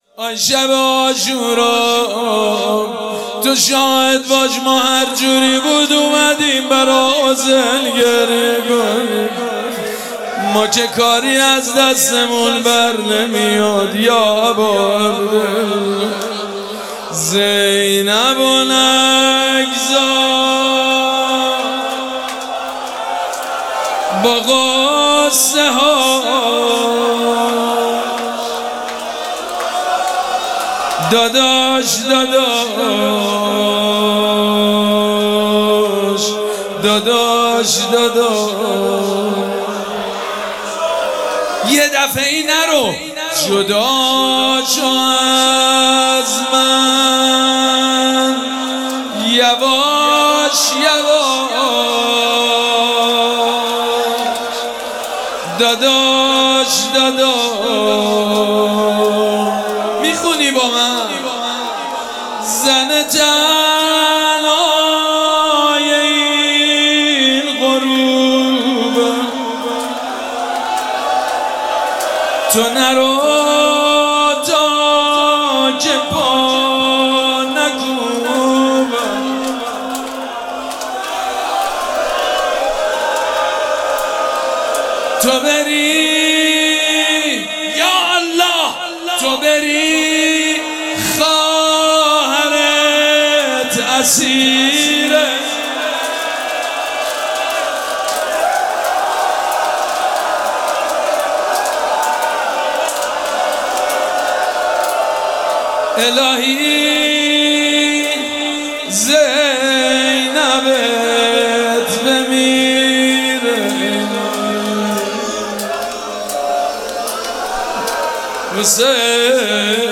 مراسم عزاداری شب دهم محرم الحرام ۱۴۴۷ شنبه ۱۴ تیر۱۴۰۴ | ۹ محرم‌الحرام ۱۴۴۷ هیئت ریحانه الحسین سلام الله علیها
روضه